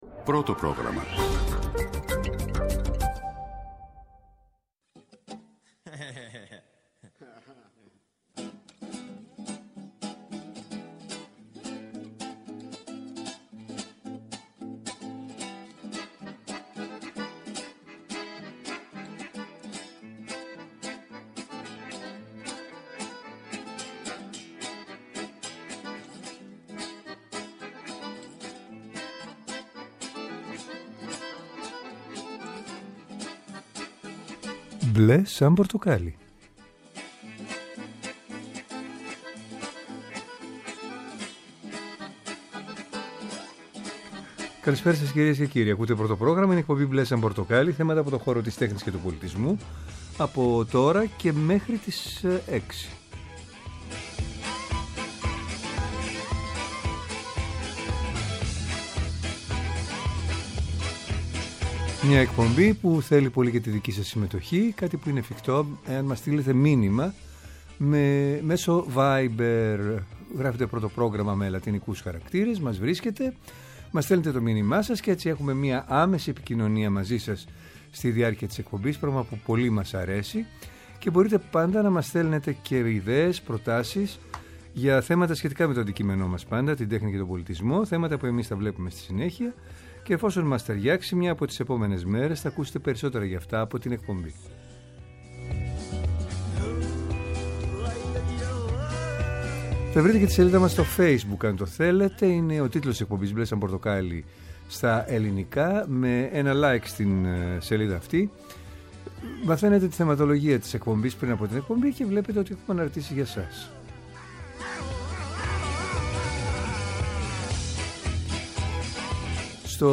Τηλεφωνικά καλεσμένοι μας είναι οι: